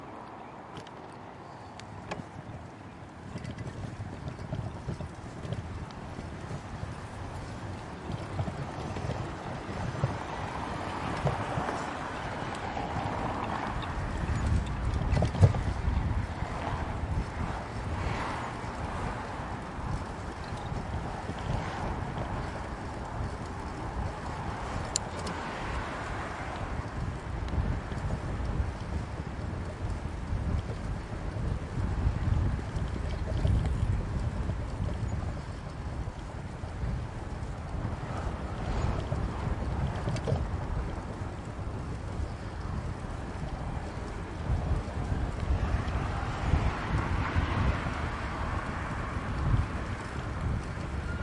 自行车通过交通SR " 140619 自行车 05 F
描述：在晚春的一个晚上，骑自行车穿越城市交通（莱比锡/德国）的4ch环绕录音。用一个Zoom H2和一个Rycote挡风玻璃安装在踏板上方中央框架上的防震支架上。所有录音都是来自录音机的原始设备通常需要一个高通滤波器来处理隆隆声。 沿着一条维护严重的道路，路过的交通繁忙。 这些是FRONT通道，麦克风设置为90°色散。
Tag: 周期 嘈杂 自行车 齿轮 机械 交通 自行车 环绕 街道 汽车 现场记录 蹬踏 铮铮 城市